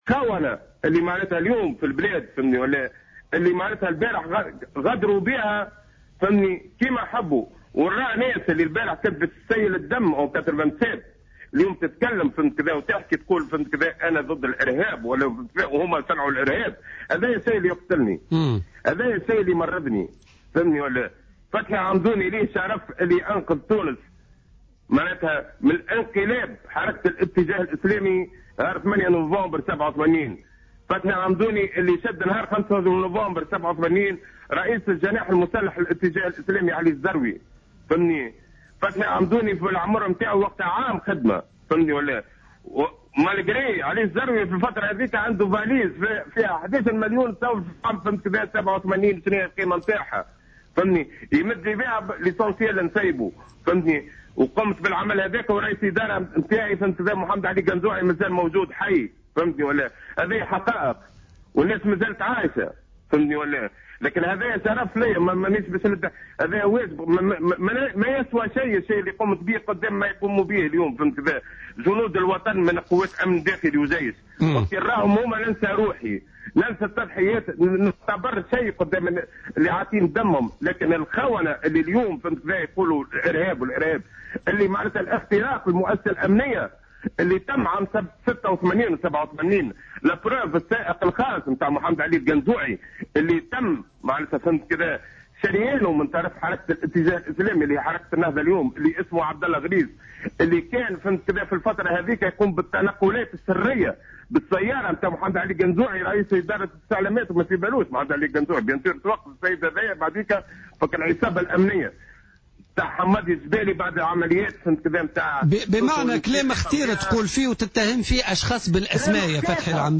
Un ancien haut responsable du ministère de l’Intérieur, Fethi Amdouni, a déclaré lors d’une intervention à l’émission « Politica » ce Mercredi 8 Avril 2015, qu’il a sauvé la Tunisie d’un bain de sang le 8 Novembre 1987.